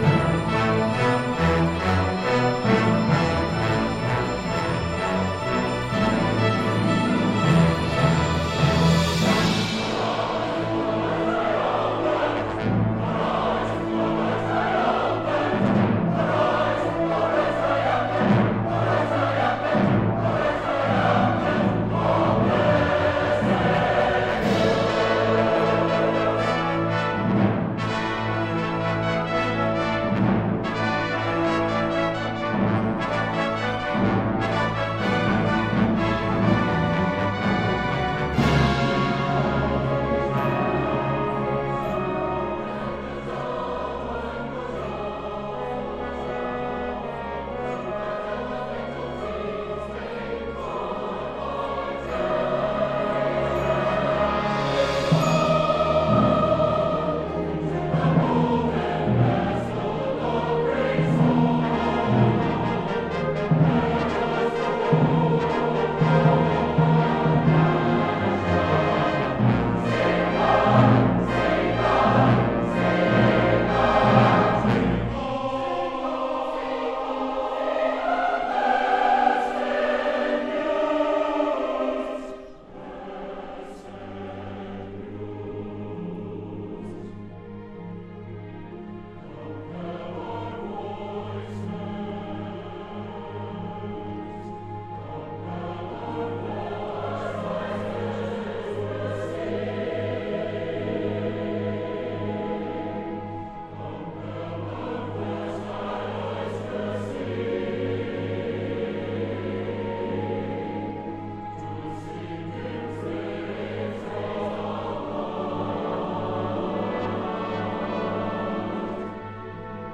Ensemble: Full Orchestra, Chorus, SATB Chorus
Instrument / Voice: Mezzo-Soprano (voice)